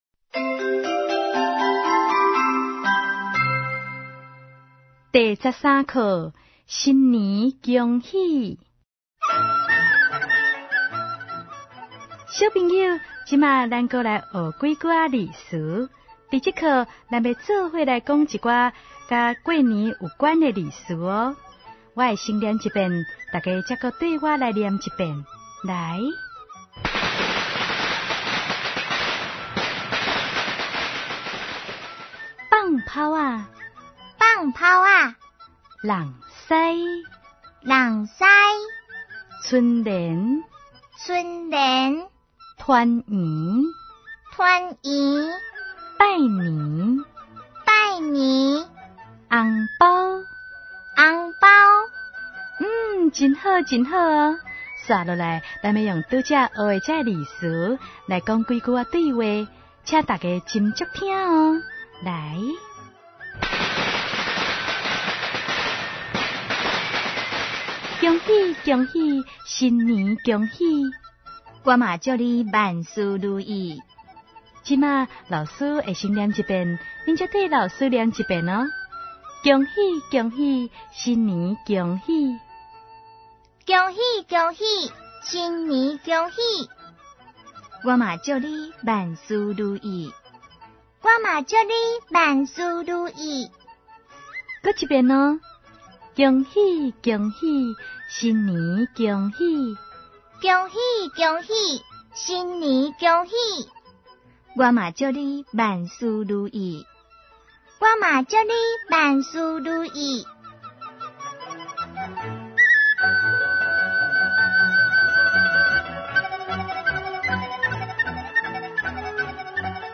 ● 兒歌唱遊、常用語詞、短句對話 ●